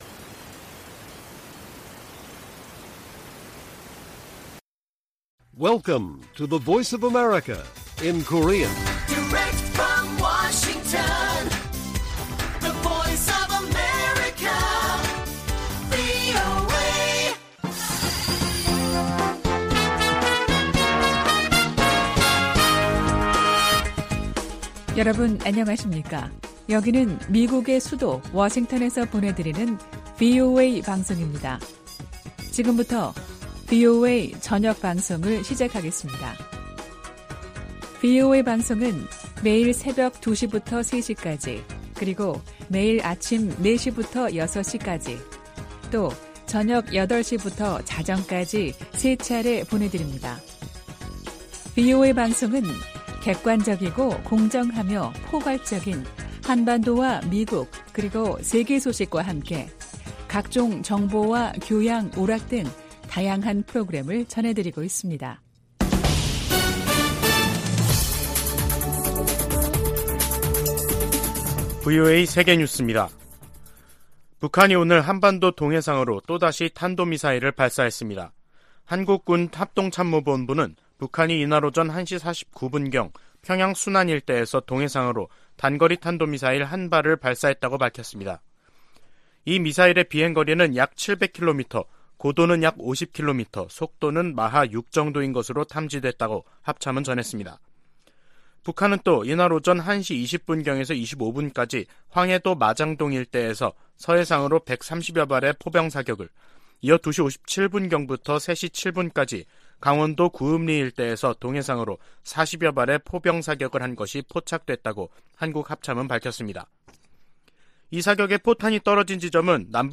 VOA 한국어 간판 뉴스 프로그램 '뉴스 투데이', 2022년 10월 14일 1부 방송입니다. 북한이 포 사격을 포함해 군용기 위협 비행, 탄도미사일 발사 등 무차별 심야 도발을 벌였습니다. 한국 정부가 북한의 노골화되는 전술핵 위협에 대응해, 5년 만에 대북 독자 제재에 나섰습니다. 미국이 로널드 레이건 항모강습단을 동원해 한국·일본과 실시한 연합훈련은 역내 안정 도전 세력에 대한 단합된 결의를 보여준다고 미 7함대가 밝혔습니다.